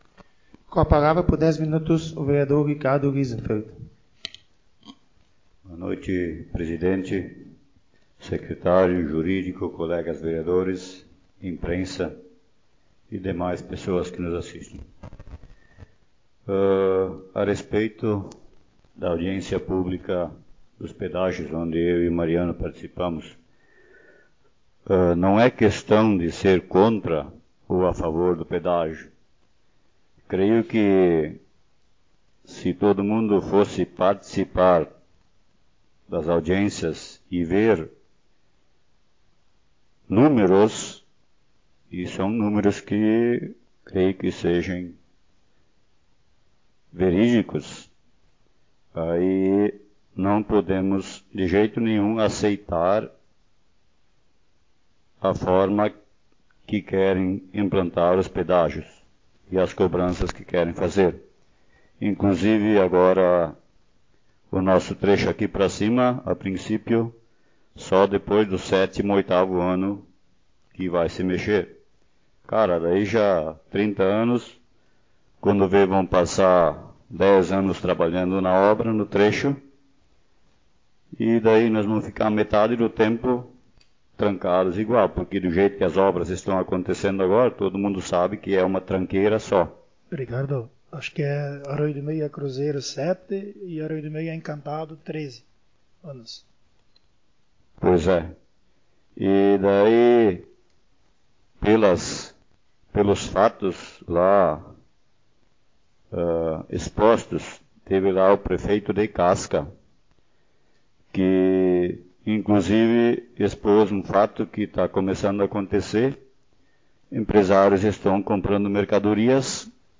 Modalidade: Áudio das Sessões Vereadores